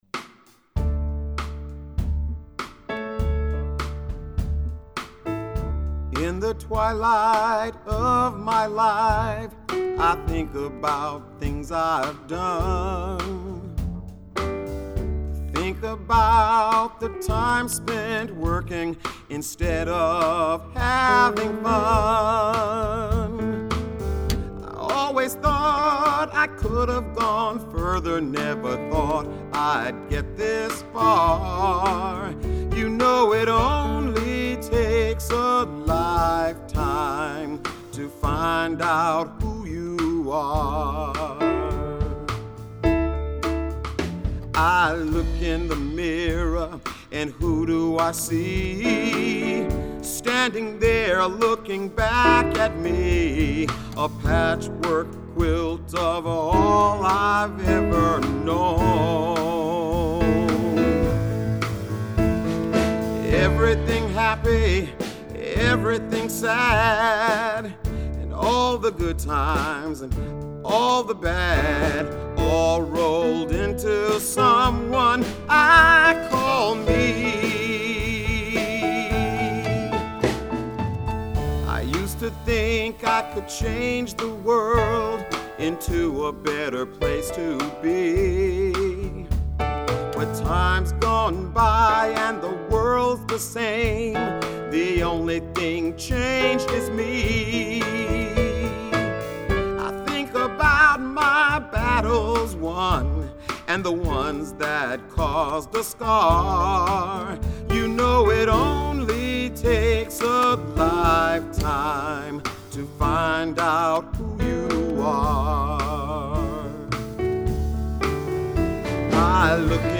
piano
vocals